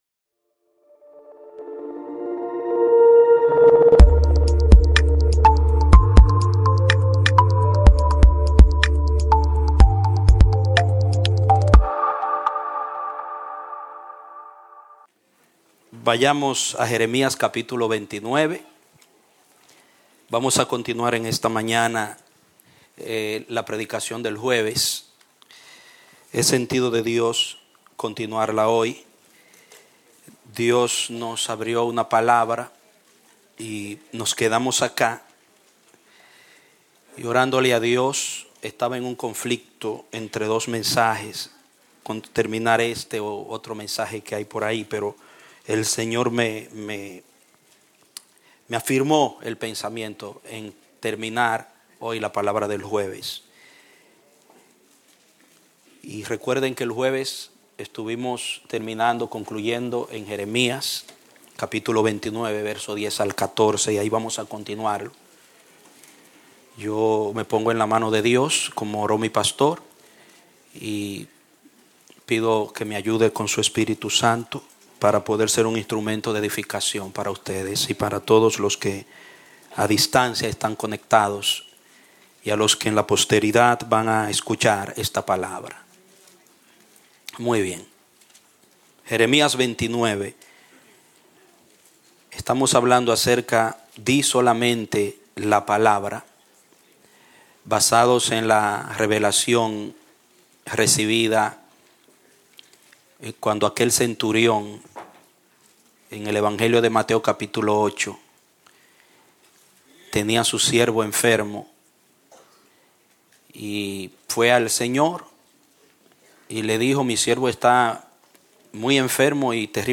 Un mensaje de la serie "Solamente la Palabra."